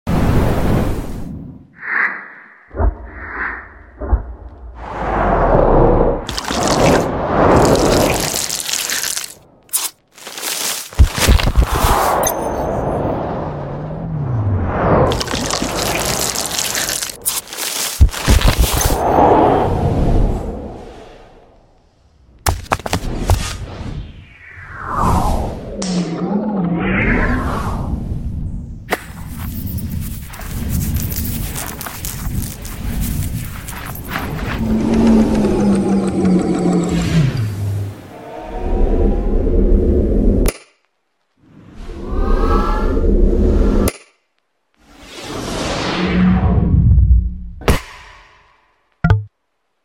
🔊 Sound re design Nintendo Switch sound effects free download